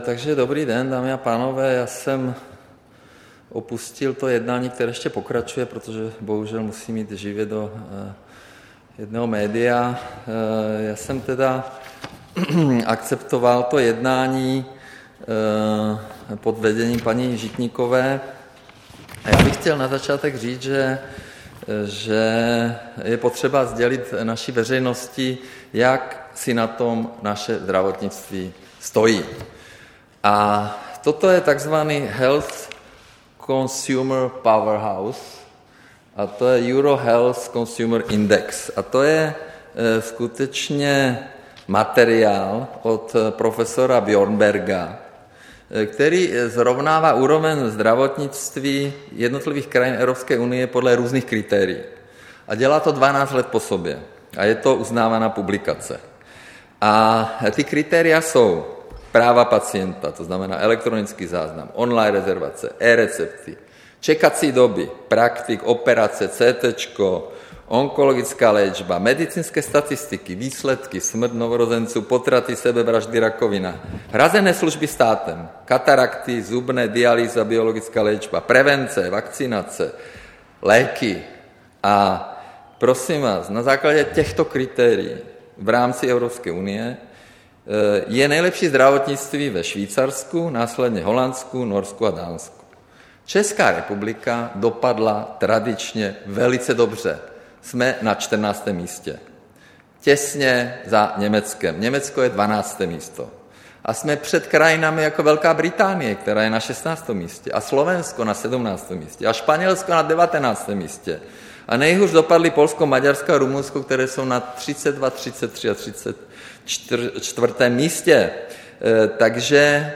Tisková konference po setkání se zástupci odborových svazů ve zdravotnictví, 11. září 2019.